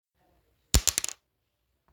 Sound Effects
Coin Fall
Coin fall.m4a